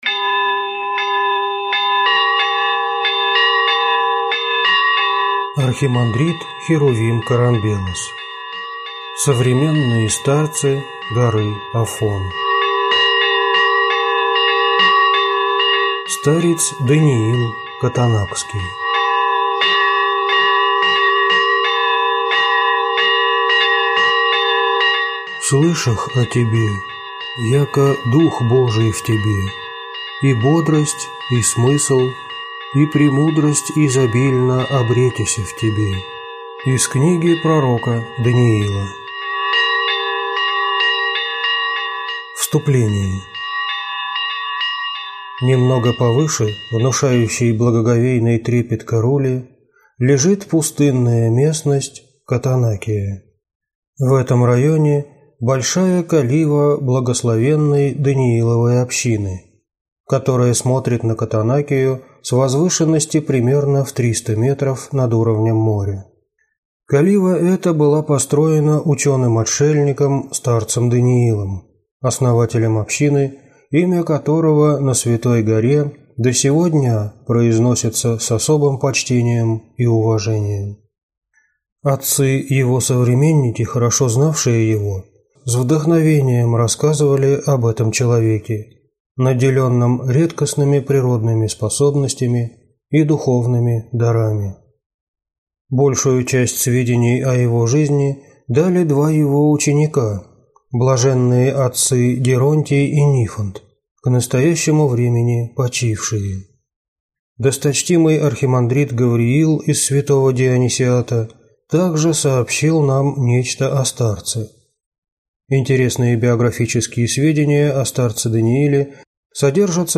Аудиокнига Старец Даниил Катонакский | Библиотека аудиокниг